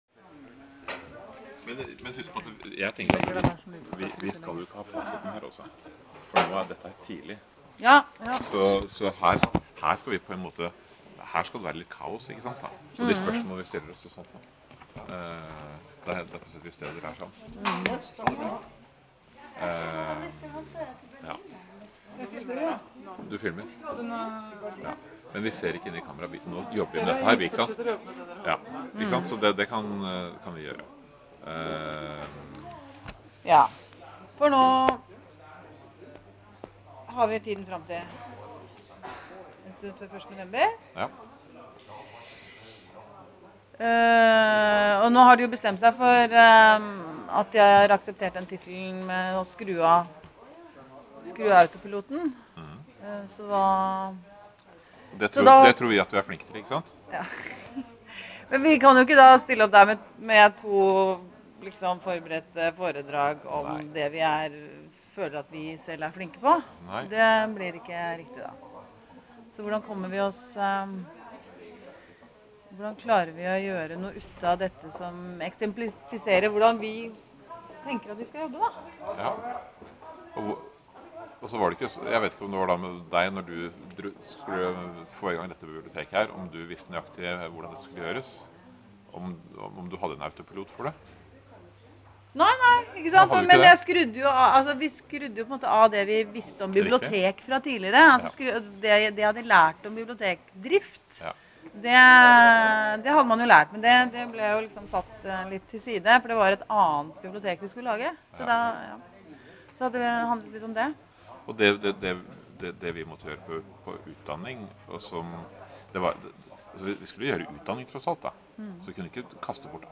i samtale